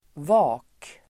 Uttal: [va:k]